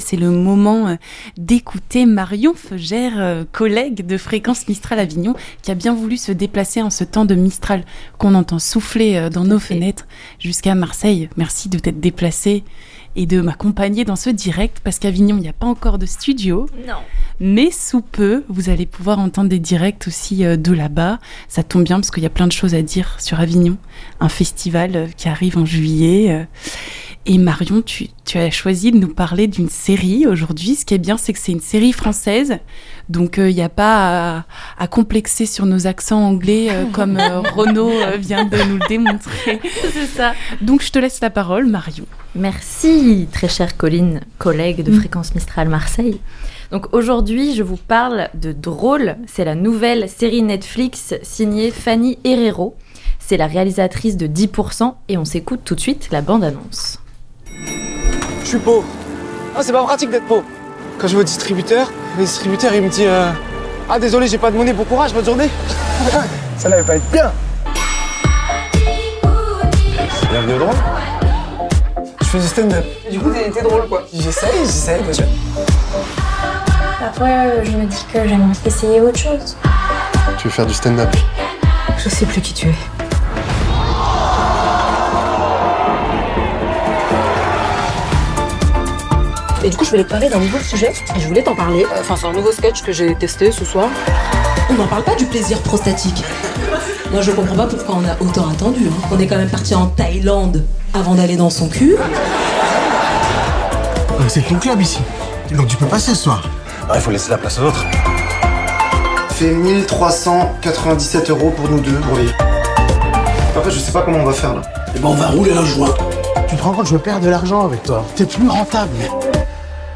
CHRONIQUE CINEMA/SERIES : "DRÔLE"